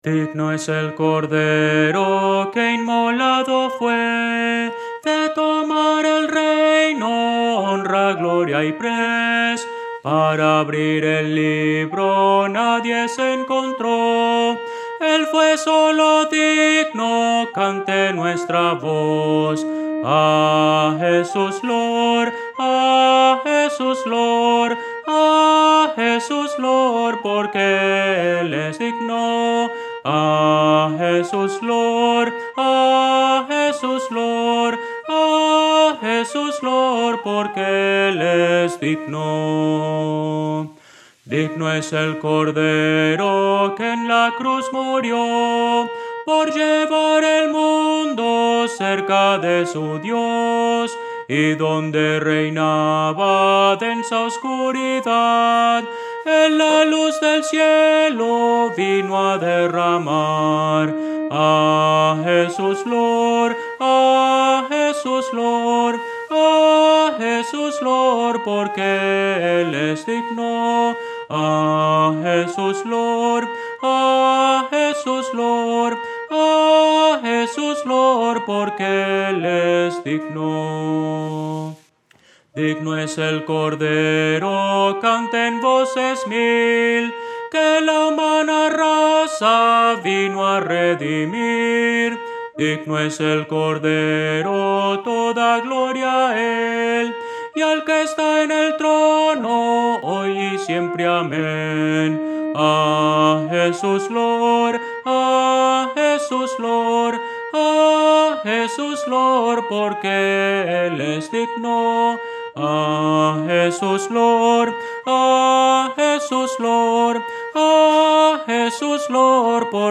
Voces para coro
Soprano – Descargar